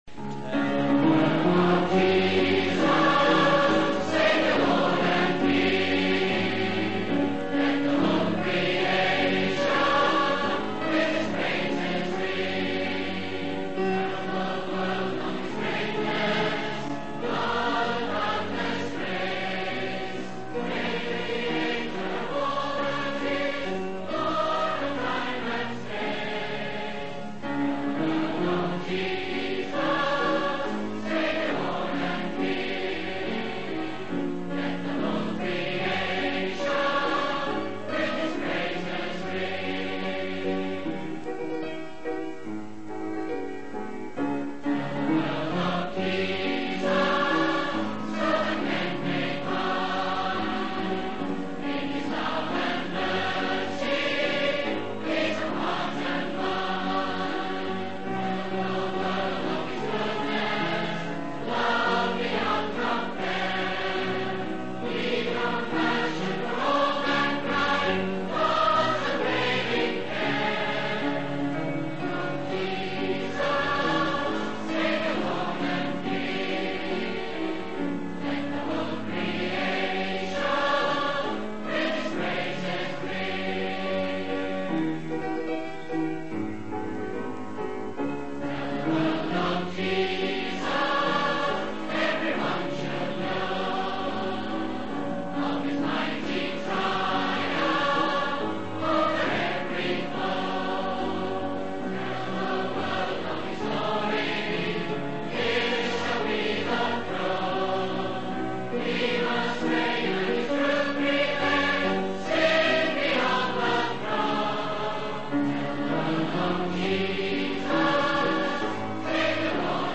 General Missionary Meeting at Keswick